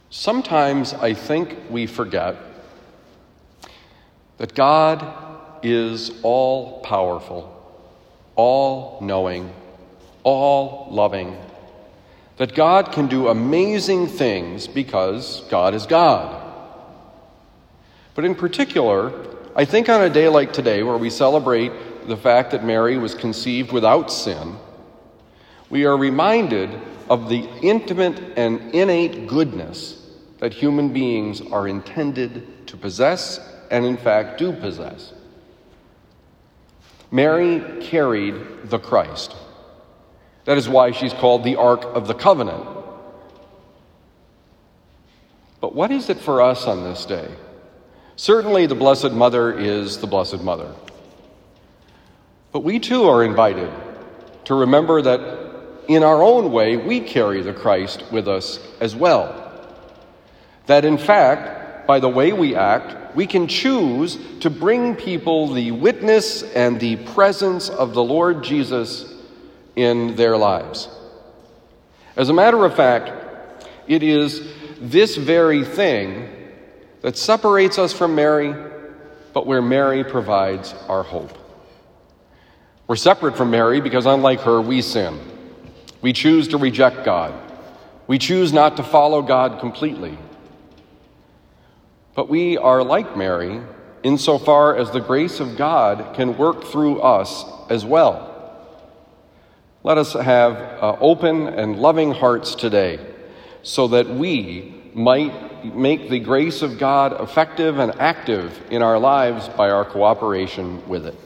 Homily for December 8, 2020 – The Friar